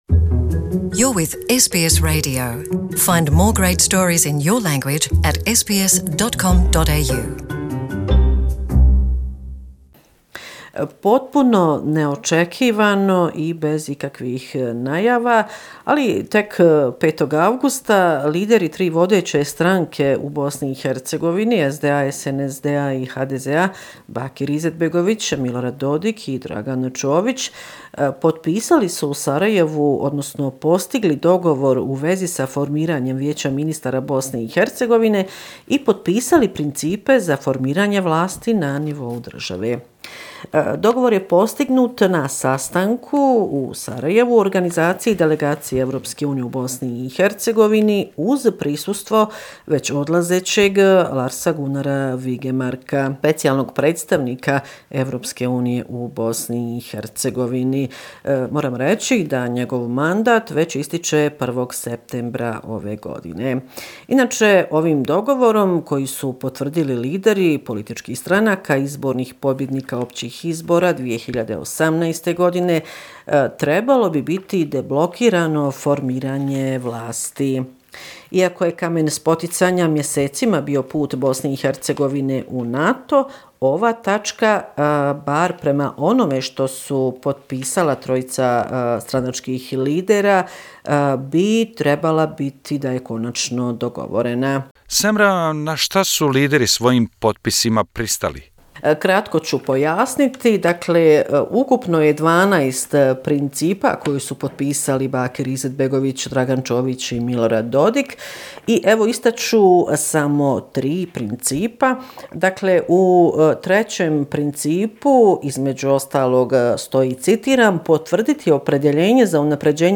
Bosnia and Herzegovina - affairs in the country for the last seven day, weekly report August 11, 2019